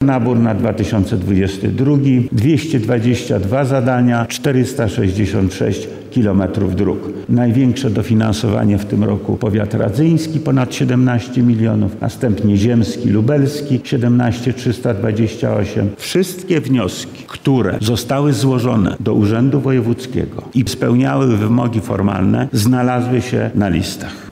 – Bariera dostępności komunikacyjnej jest jedną z ważniejszych przyczyn, które utrudniają rozwój gospodarczy, szczególnie przemysłu – mówi wojewoda lubelski Lech Sprawka.